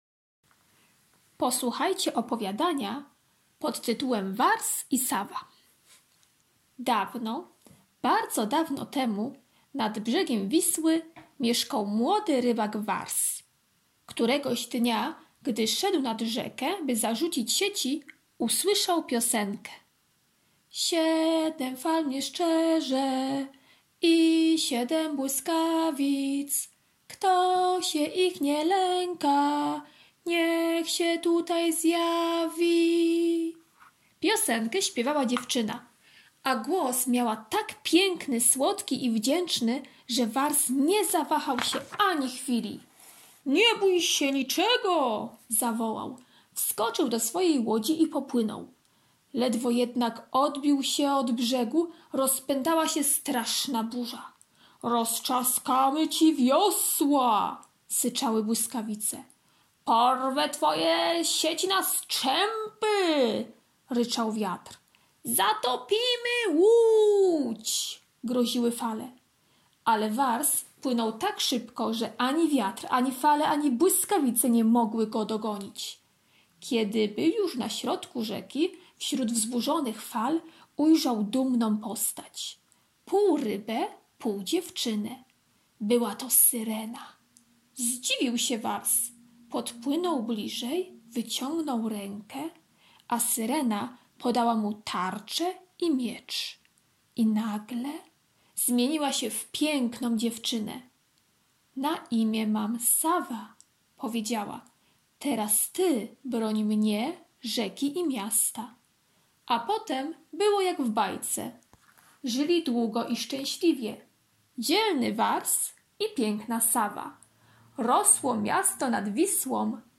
wtorek - opowiadanie "Wars i Sawa" [3.96 MB] wtorek - prezentacja "Warszawa" [5.05 MB] wtorek - praca plastyczna "Warszawska syrenka" [190.00 kB] wtorek - ćw. dla chętnych - pisanie litery ł, Ł [24.18 kB]